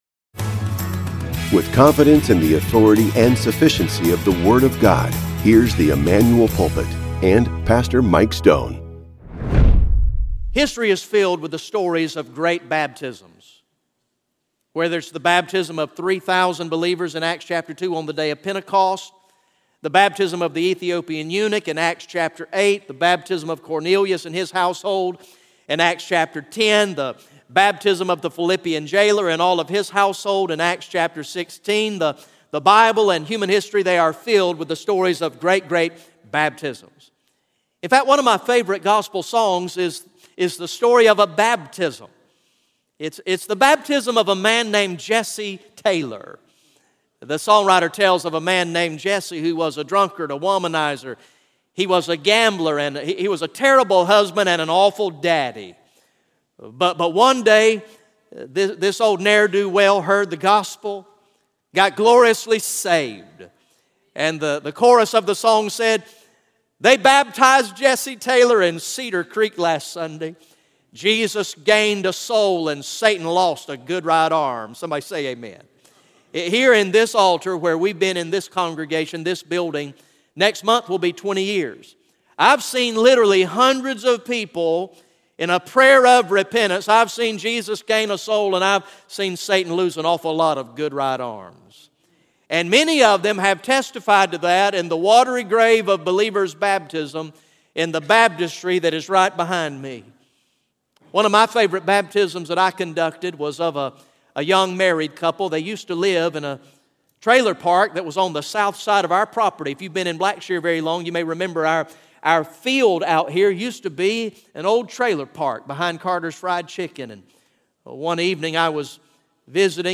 GA Message #09 from the sermon series entitled “King of Kings